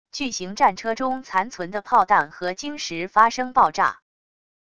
巨型战车中残存的炮弹和晶石发生爆炸wav音频